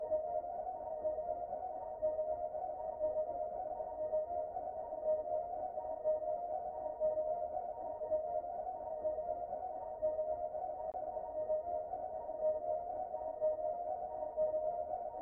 标签： 126 bpm Trap Loops Bells Loops 2.56 MB wav Key : Unknown
声道立体声